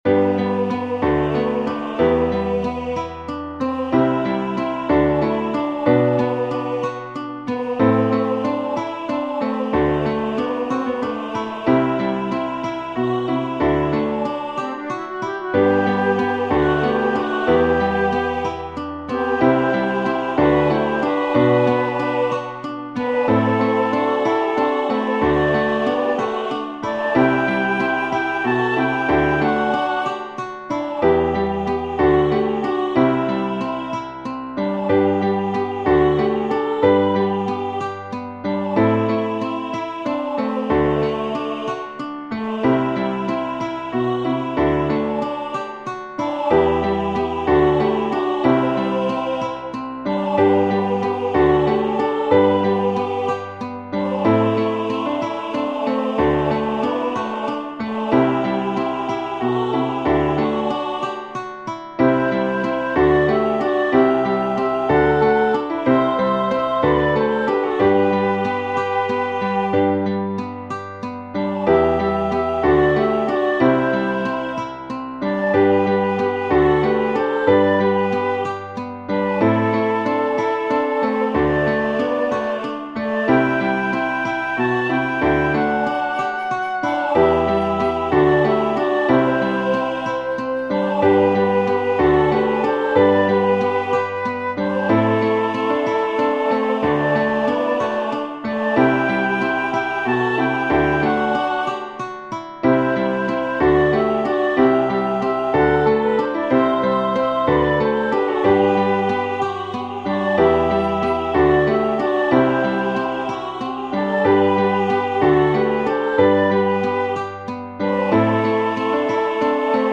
δίφωνη χορωδία, φλάουτο, κιθάρα και συγχορδίες, Gmaj